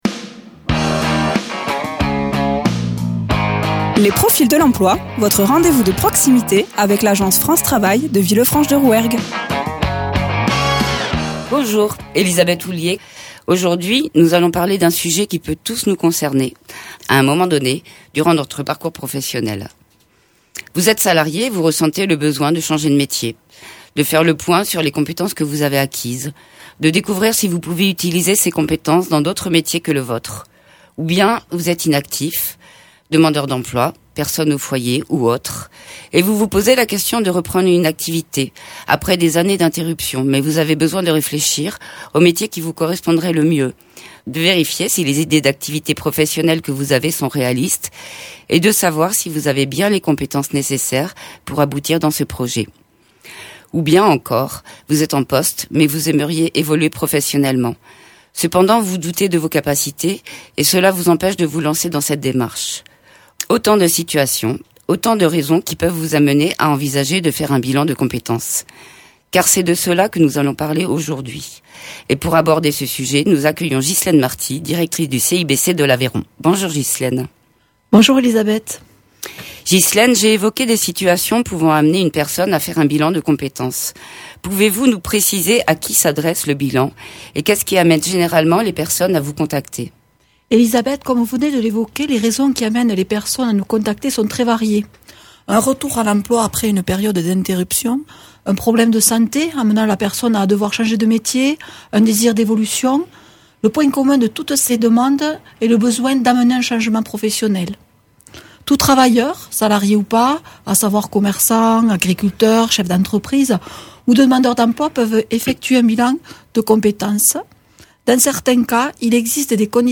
Interviews
Présenté par Les conseillers de France Travail,